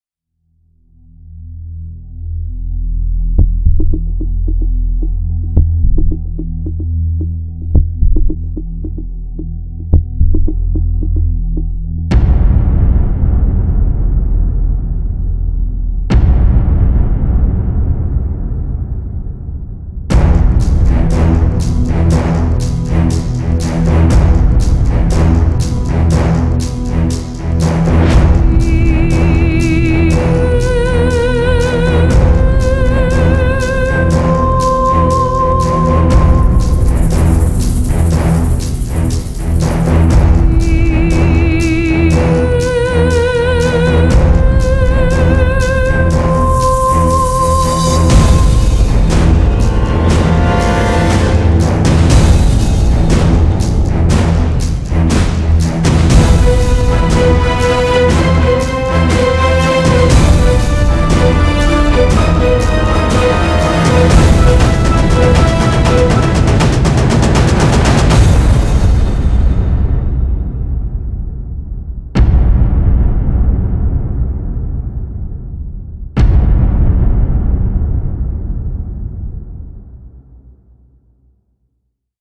Lyricist: Instrumental